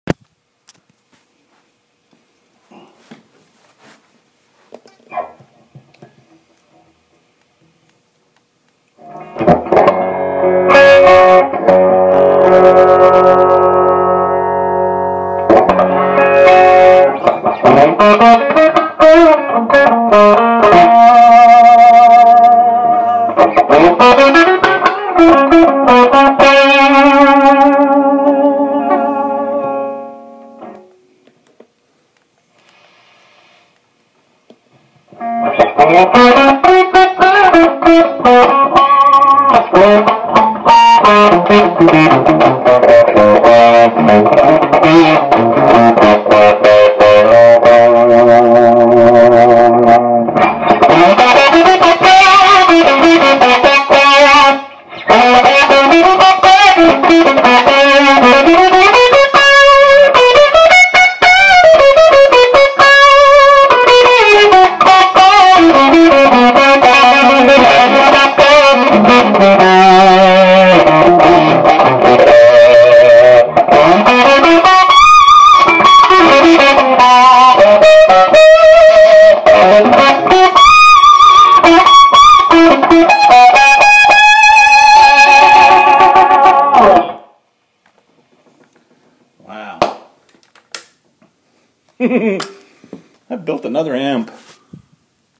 Here are a couple of down and dirty sound clips. Recorded on iPhone with open air speaker.
This is without NFB and with a GZ34 tube.